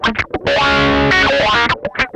MANIC WAH 7.wav